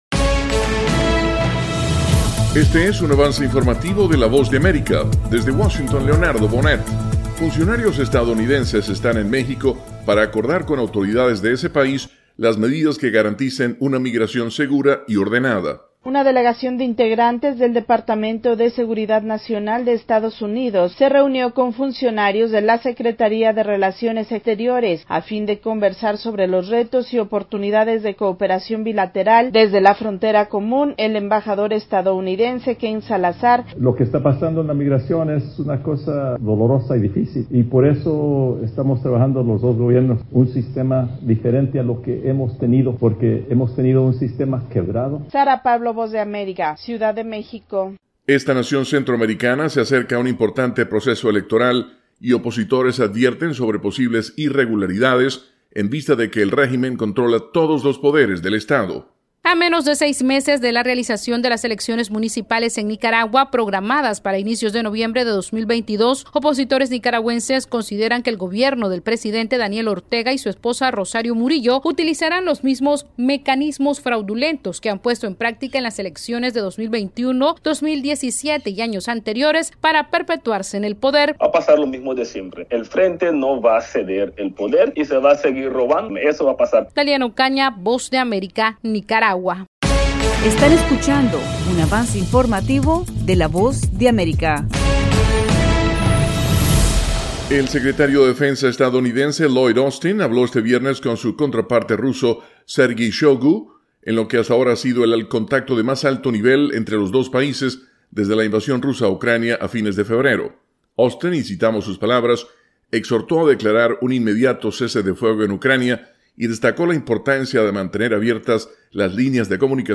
Avance Informativo
El siguiente es un avance informativo presentado por la Voz de América, desde Washington